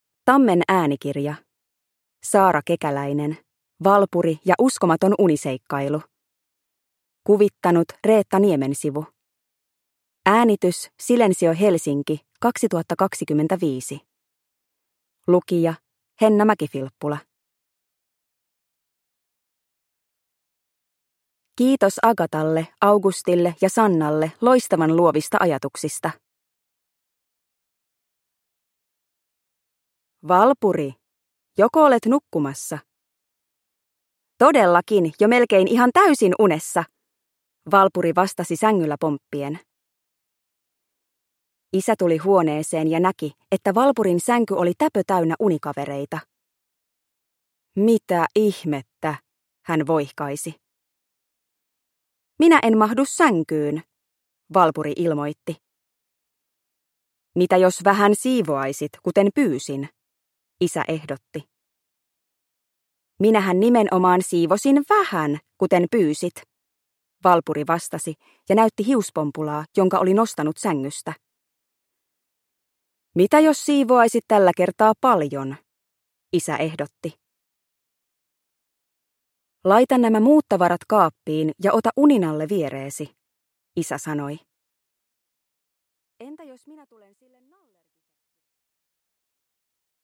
Valpuri ja uskomaton uniseikkailu – Ljudbok